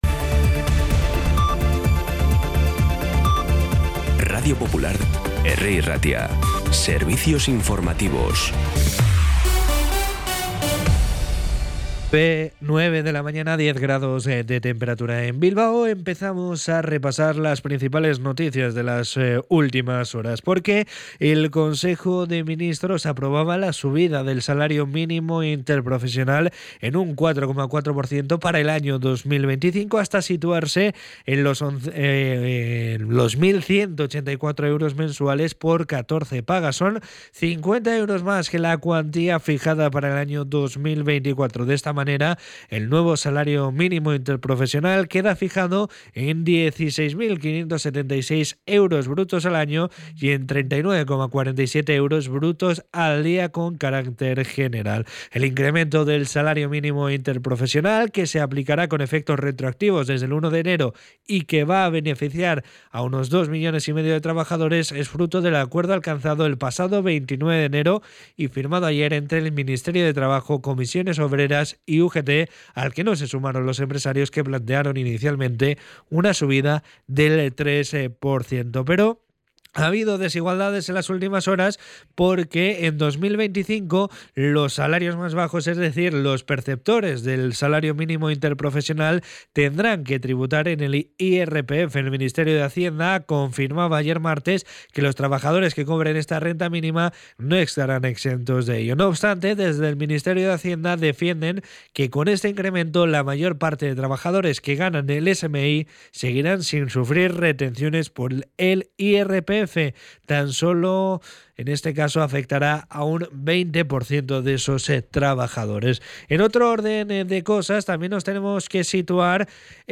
Las noticias de Bilbao y Bizkaia del 12 de febrero a las 9
Los titulares actualizados con las voces del día.